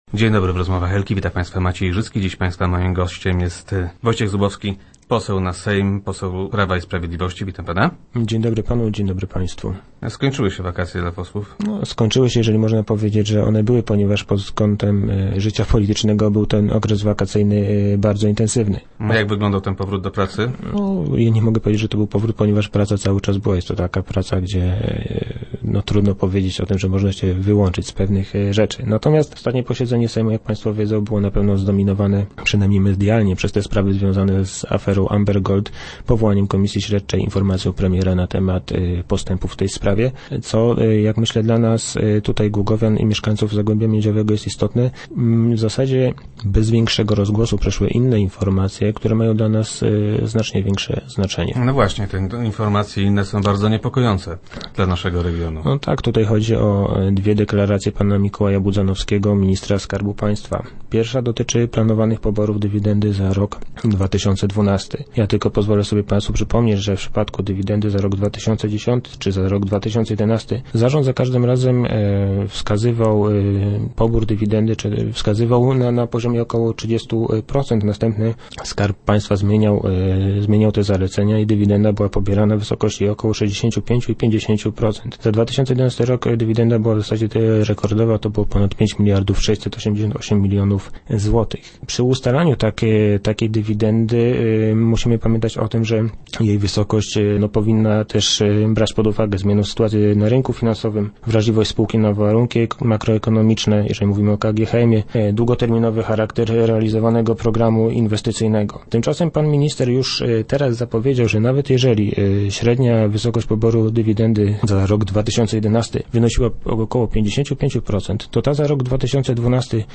- Trudno zgodzić się z takim działaniem ministra – twierdzi poseł Wojciech Zubowski, członek komisji skarbu państwa.
Tymczasem pan minister zapowiedział, że o ile dywidenda za rok 2011 wynosiła około 55 procent, to ta za rok 2012 będzie wynosiła grubo powyżej 60 procent, a w przypadku spółek notowanych na giełdzie wysokość dywidendy może wynosić aż 80 procent - mówił na radiowej antenie poseł Zubowski.